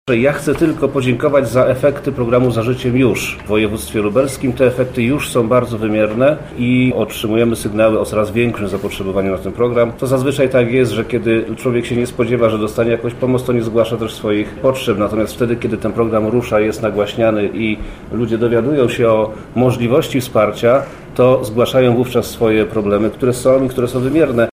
-mówi Przemysław Czarnek, Wojewoda Lubelski,